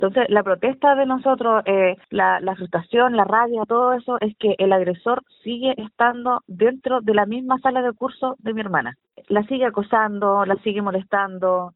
En el lugar, con carteles y globos, protestaron contra el establecimiento.
Según relató una de las manifestantes, la denuncia de abuso sexual contra un alumno del colegio, se realizó por hechos ocurridos a fines de 2023 y durante el año pasado.